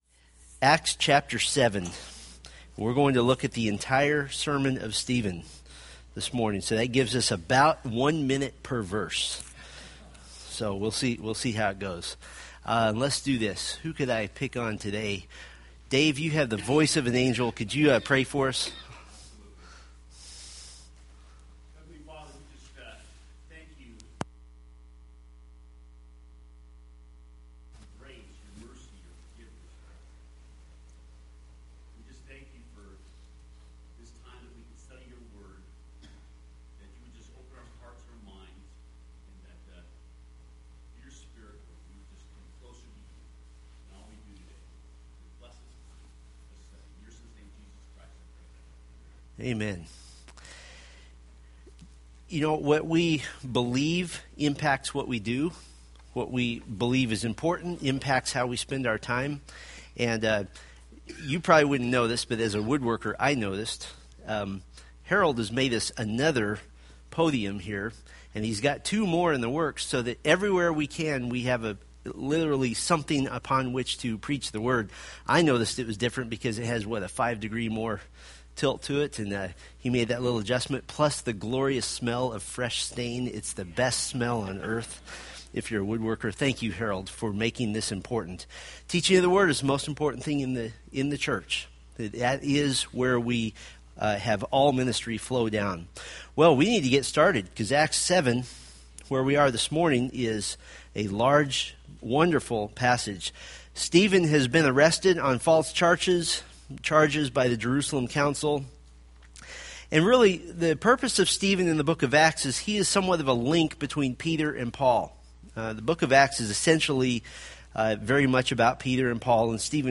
Date: Mar 2, 2014 Series: Acts Grouping: Sunday School (Adult) More: Download MP3